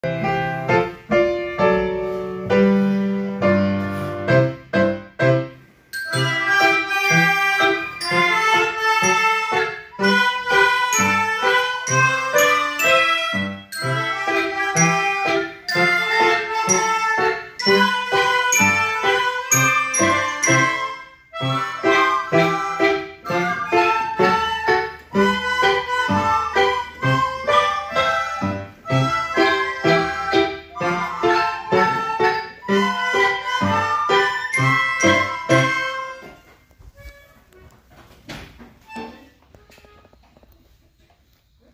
0224 4年茶色い小瓶 演奏